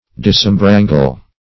Search Result for " disembrangle" : The Collaborative International Dictionary of English v.0.48: Disembrangle \Dis`em*bran"gle\, v. t. [Pref. dis- + em = en (L. in) + brangle.] To free from wrangling or litigation.